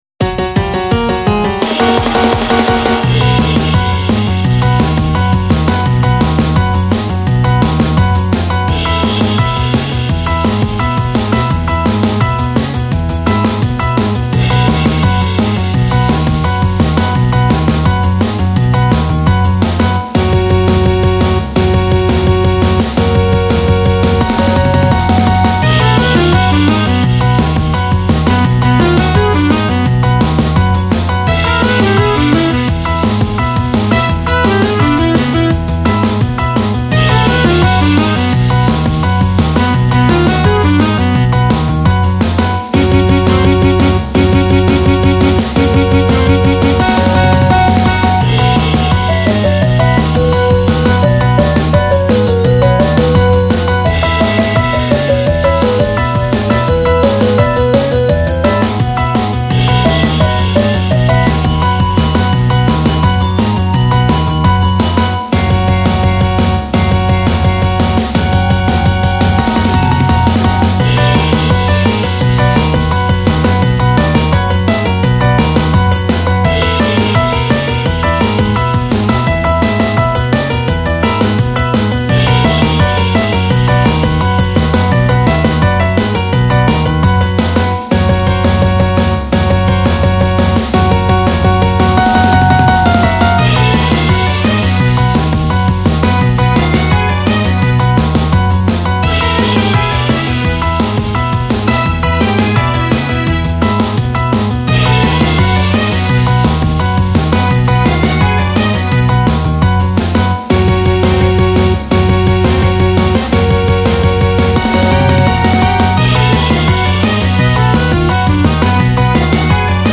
【用途/イメージ】　おもしろ動画　ダイジェスト映像　シュール　可笑しい　オチ　ドッキリ　楽しい　笑い